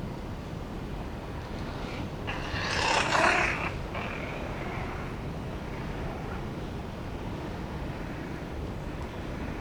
・・ムササビの鳴き声（'21年12月）・・・
いずれも撮影は徳島県石井町ほか
musa_keikai.wav